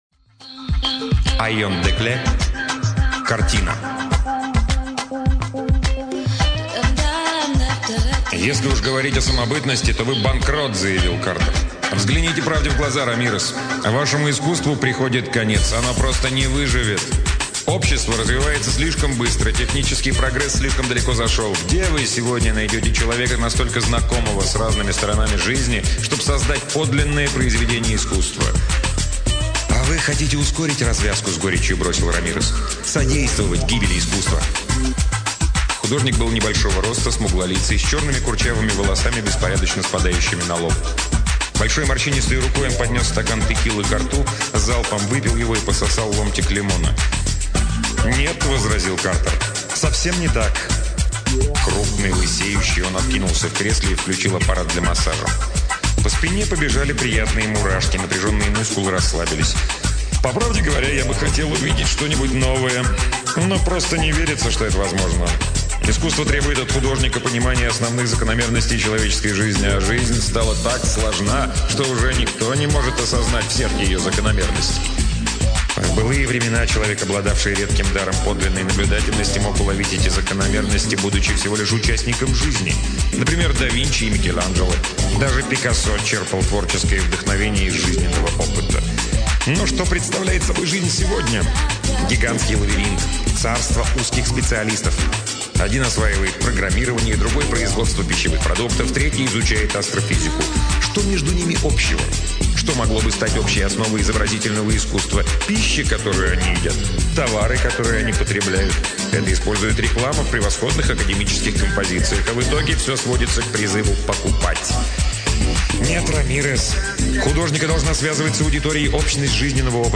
Аудиокнига Айом Декле — Картина